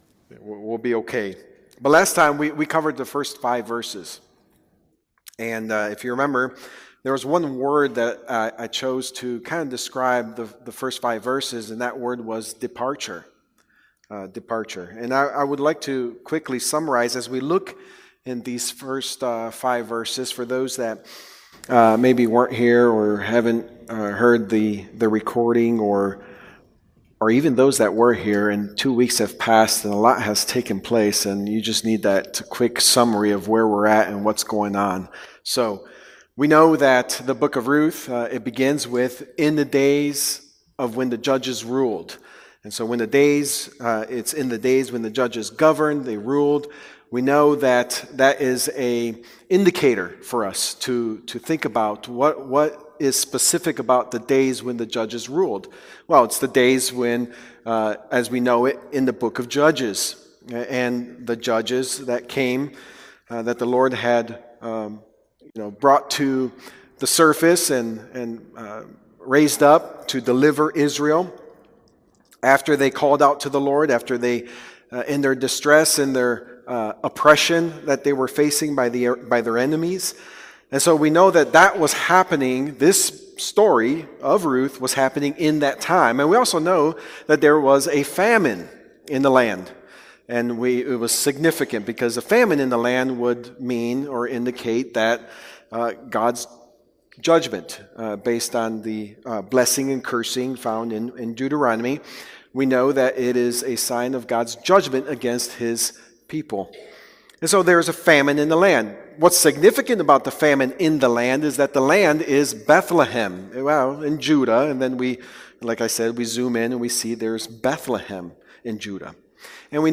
The God Who Visits | SermonAudio Broadcaster is Live View the Live Stream Share this sermon Disabled by adblocker Copy URL Copied!